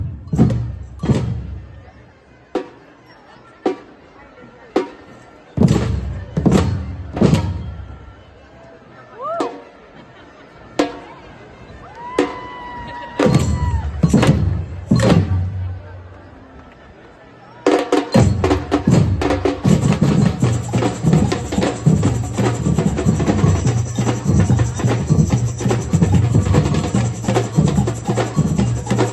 Gaderne var fyldt med gule og røde faner, solskin, sange, slagord og et stærkt kollektivt nærvær.